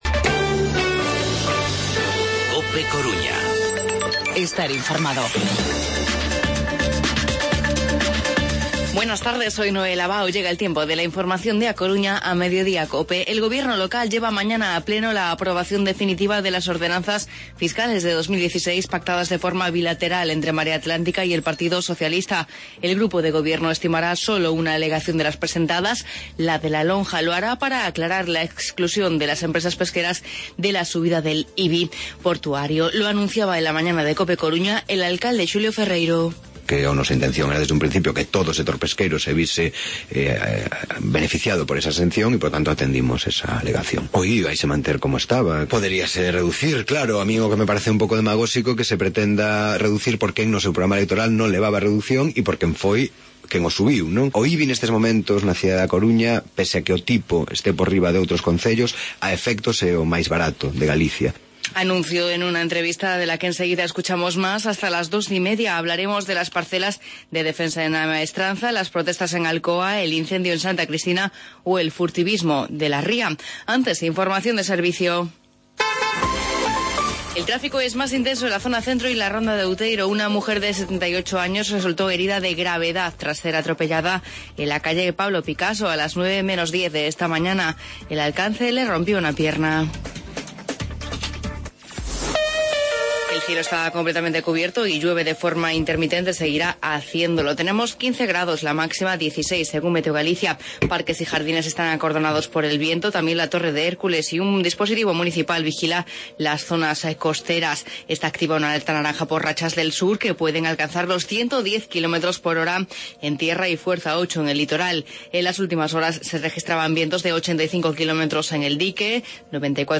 Informativo Mediodía COPE Coruña lunes, 28 de diciembre de 2015